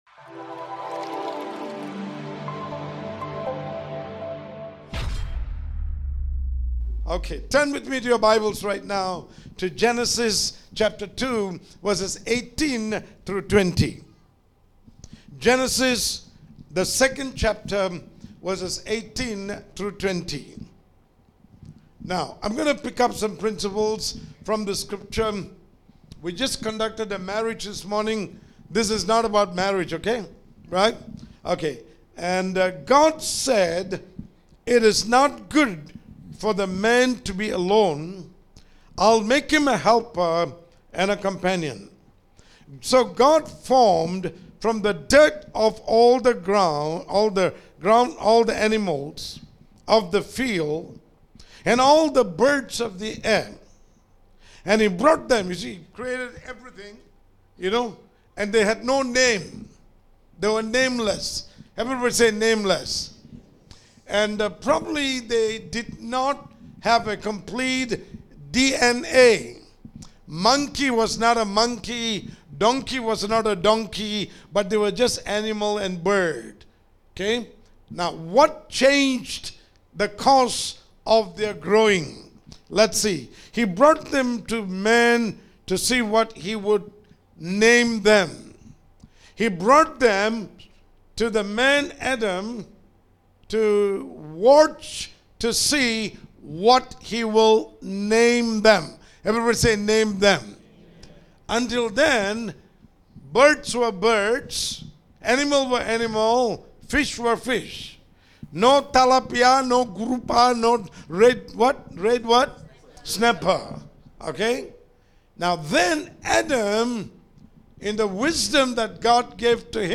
Audio Sermon | Calvary Community Church Johor Bahru